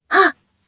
Kara-Vox_Jump_jp.wav